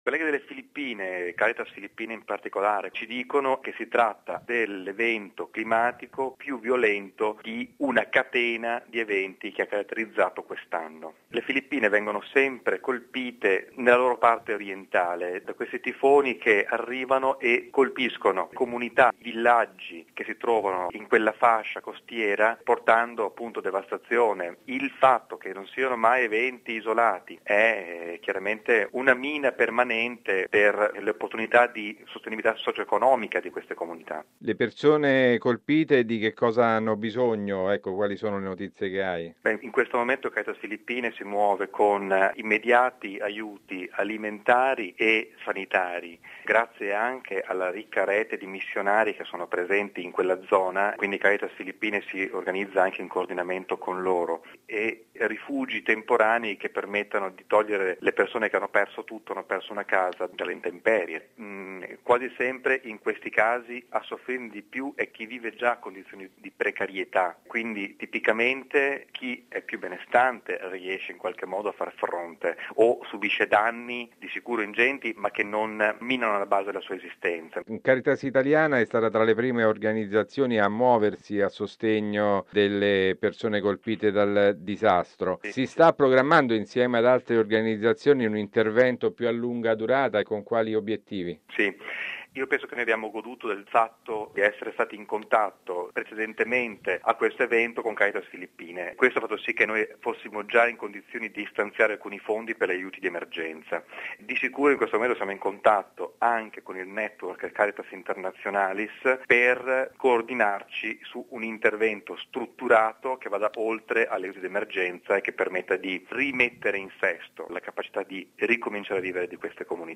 ha contattato telefonicamente